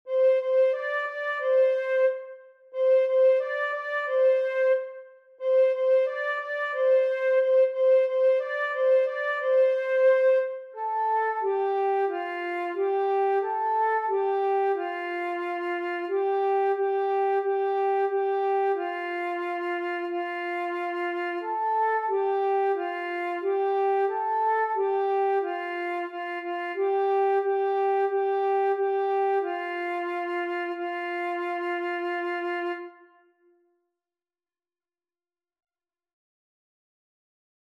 Lager, in Bes
dit liedje is pentatonisch